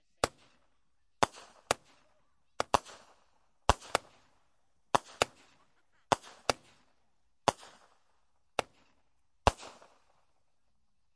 烟花 " 烟花14
描述：使用Tascam DR05板载麦克风和Tascam DR60的组合使用立体声领夹式麦克风和Sennheiser MD421录制烟花。
Tag: 高手 焰火 裂纹